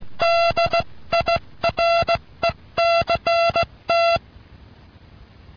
IL COLLEGAMENTO IN TELEGRAFIA